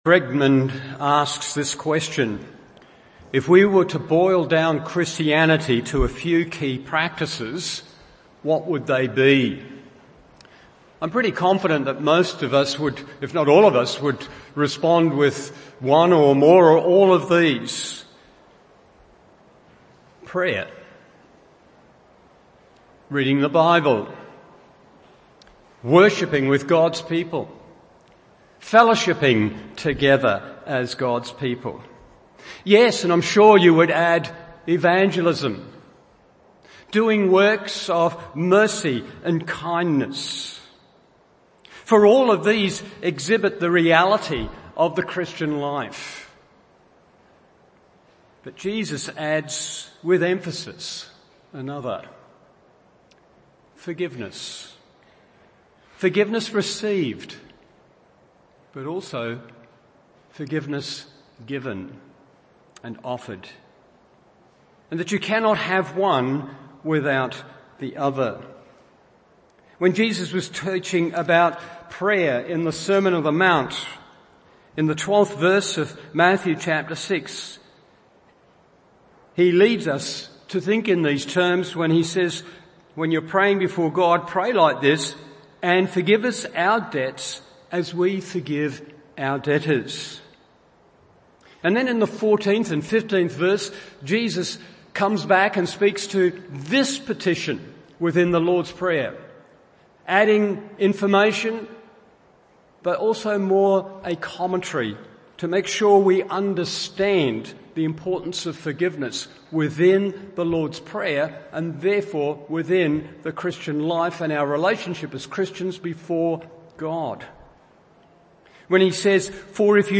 Morning Service Matt 6:12b, 14-15 1.